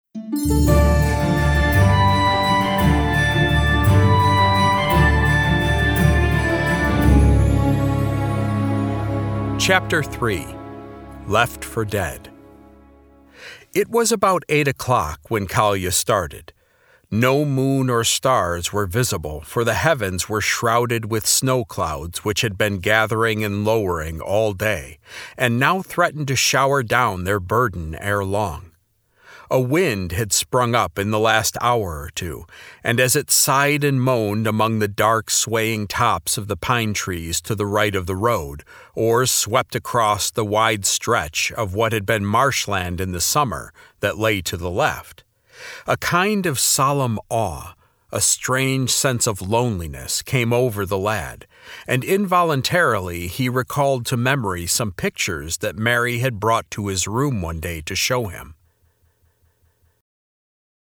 Courage-of-Nikolai-Audiobook-Sample.mp3